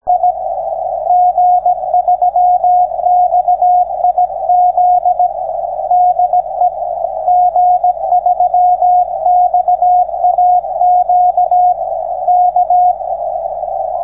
With only 3kHz to play with in the UK most of the activity on the band is on normal CW and QRSS (slow CW).
The first impression I had of the band was that signals were strong despite the low 100mW erp.
To give you an idea of what the band sounds like I have made a few recordings of stations I have received as follows: